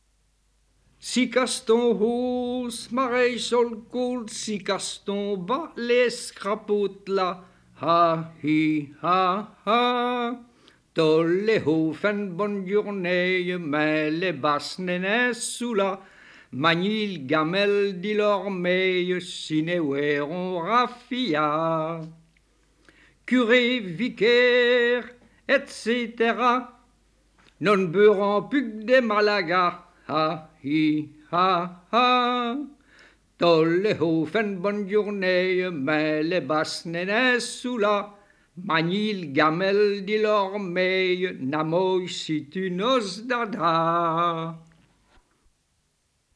Genre : chant
Type : chanson de conscrit / tirage au sort
Lieu d'enregistrement : Liège
Support : bande magnétique
Chanson de conscrit.